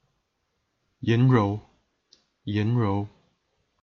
pronunciation) (fl. 193–220) was a military general of the state of Cao Wei during the Three Kingdoms period of China.